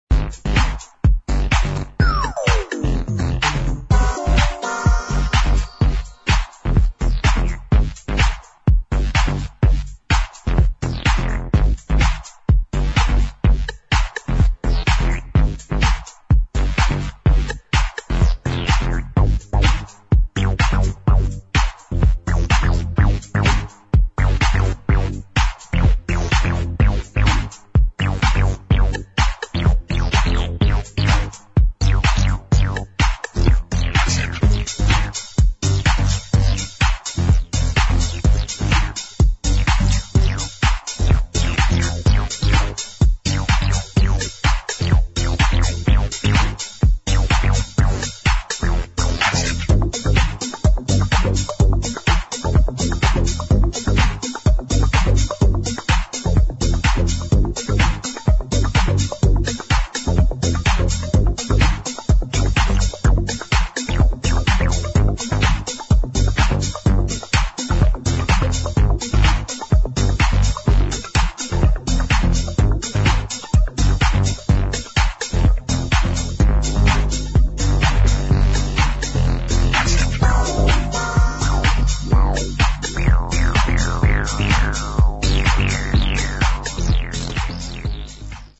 [ TECHNO / TECH HOUSE ]